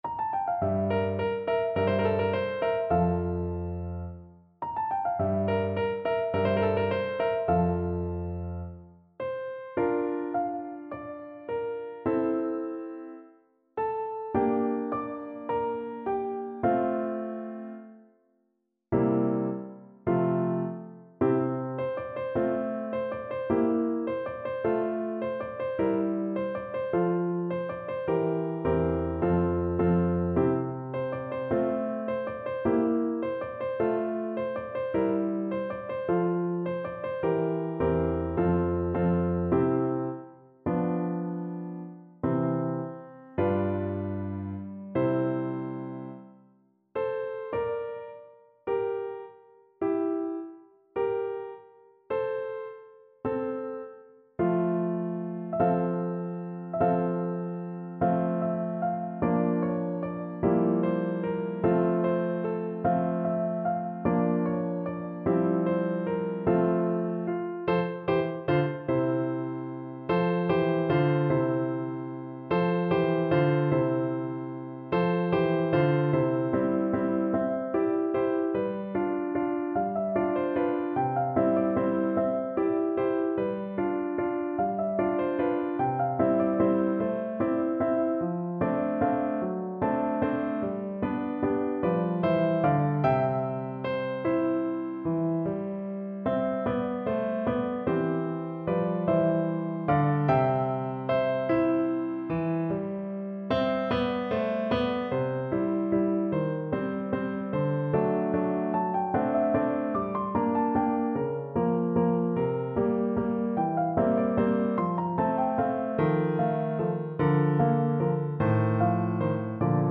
2/4 (View more 2/4 Music)
Allegretto =76
Classical (View more Classical Soprano Voice Music)